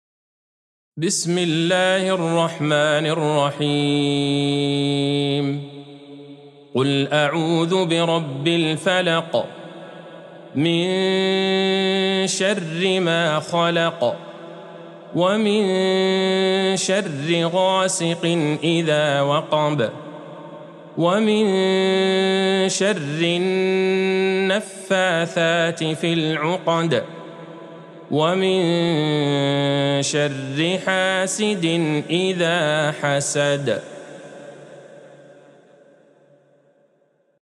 سورة الفلق Surat Al-Falaq | مصحف المقارئ القرآنية > الختمة المرتلة ( مصحف المقارئ القرآنية) للشيخ عبدالله البعيجان > المصحف - تلاوات الحرمين